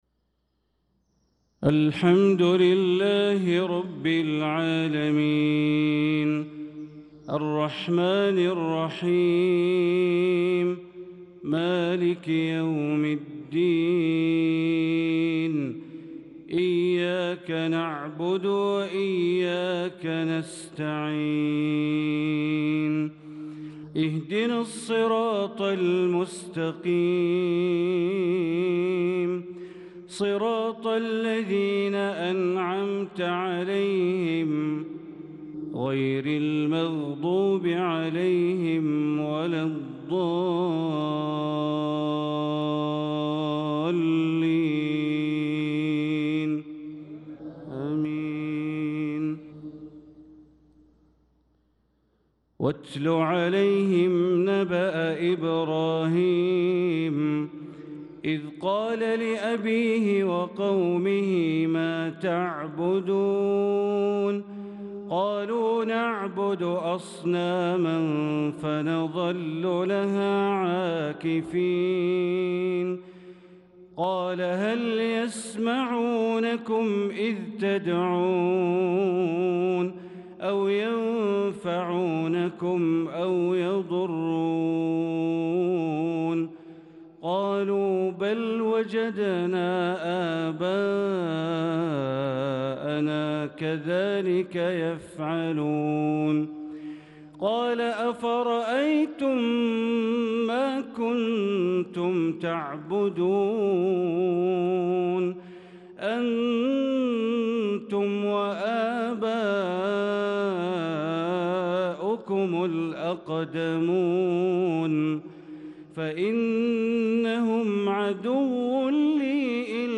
صلاة المغرب للقارئ بندر بليلة 18 ذو القعدة 1445 هـ